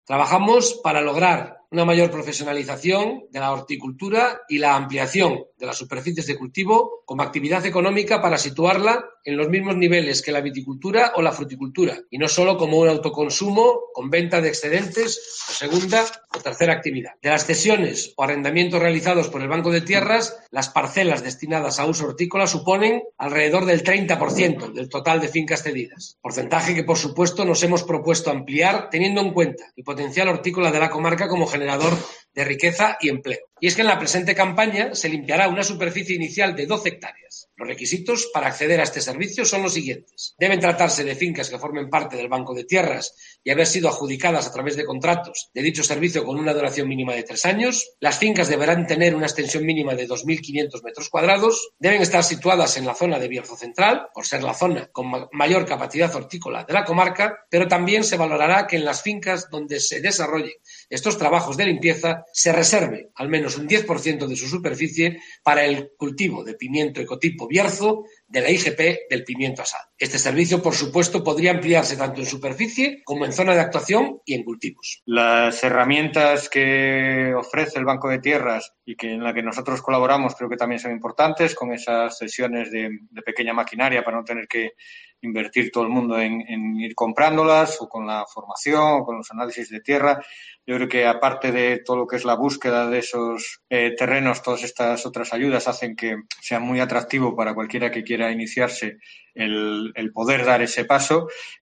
Escucha aquí las palabras del presidente del Consejo Comarcal del Bierzo, Gerardo Álvarez Courel